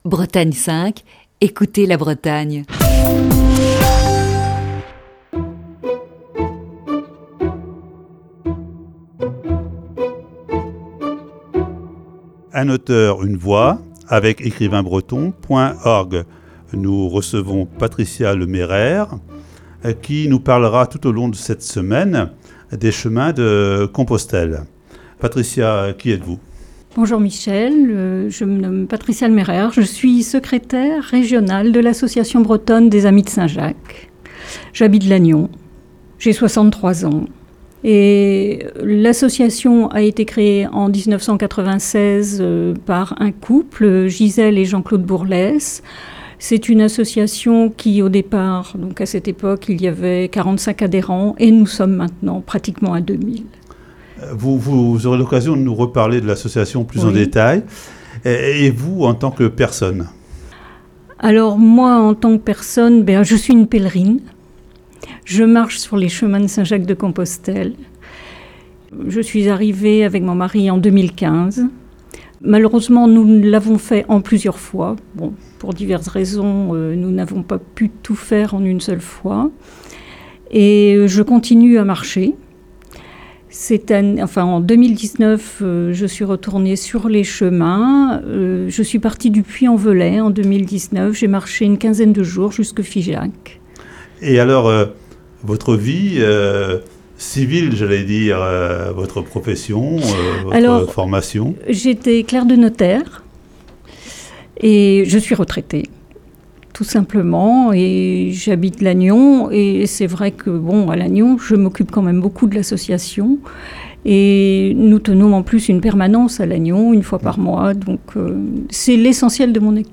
Voici ce lundi, la première partie de cet entretien diffusé le 10 février 2020.